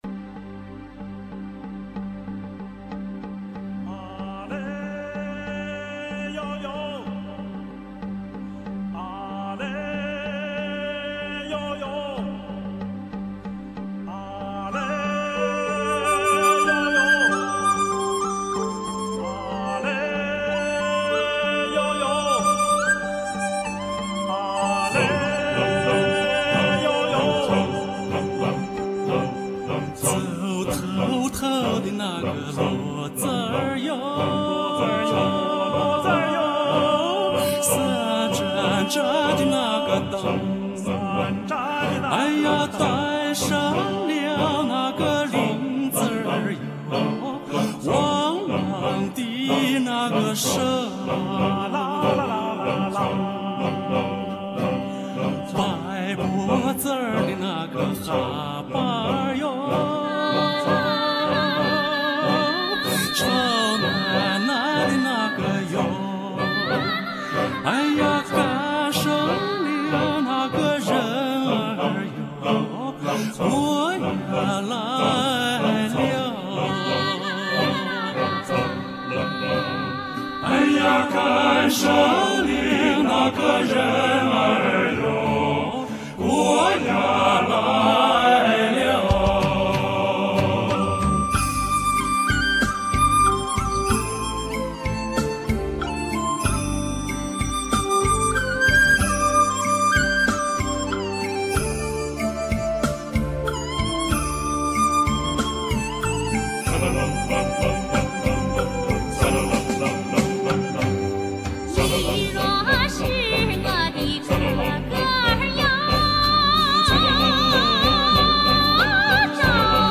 [分享]陕北民歌“赶牲灵”由北京四兄弟演唱组演唱